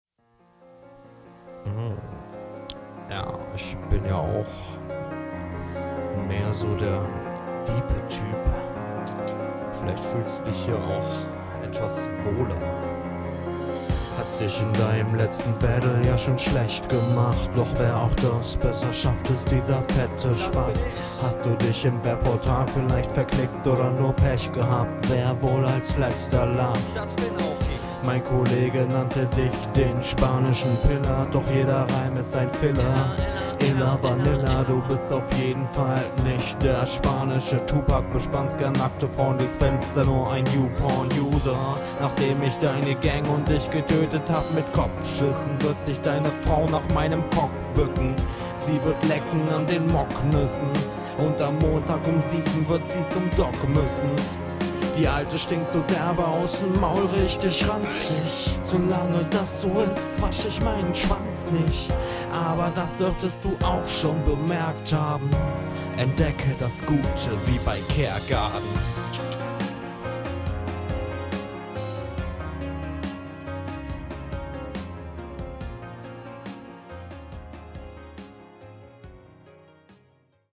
Erneut starkes rauschen.
Beat und Rap sind leider sehr lahm.